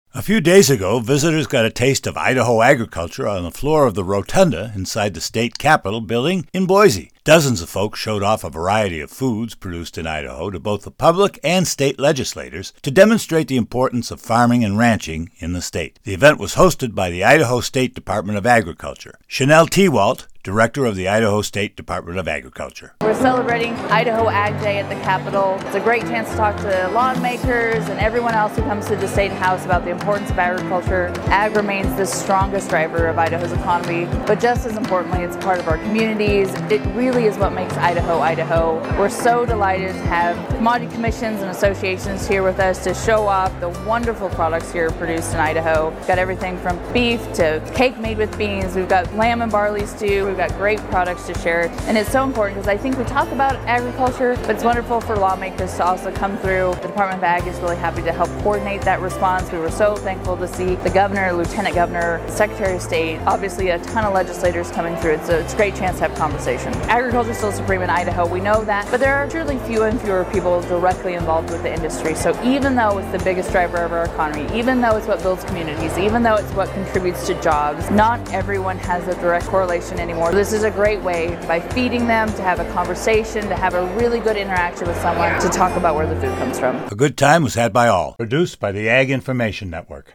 On Jan 13th visitors got a taste of Idaho Agriculture on the floor of the rotunda inside the state capitol building in Boise. Dozens of folks showed off a variety of foods produced in Idaho to both the public and state legislators to demonstrate the importance of farming and ranching in the state.